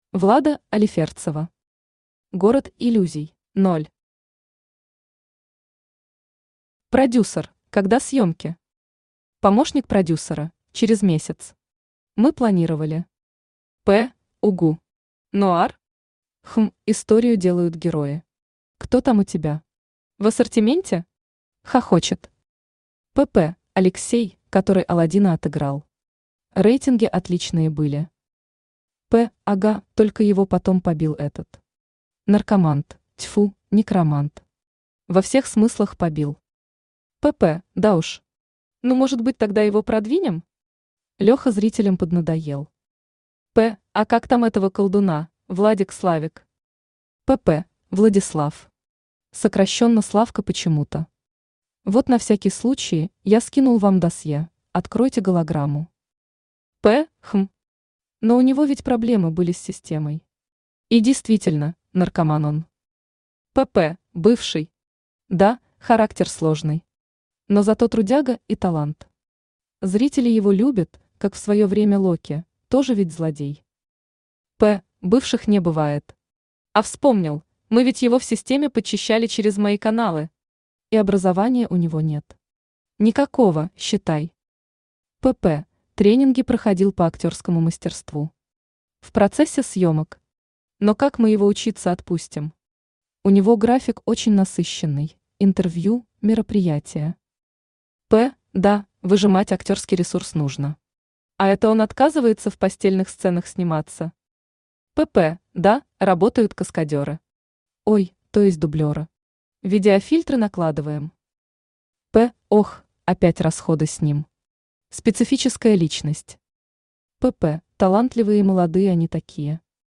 Аудиокнига Город иллюзий | Библиотека аудиокниг
Aудиокнига Город иллюзий Автор Влада Алиферцева Читает аудиокнигу Авточтец ЛитРес.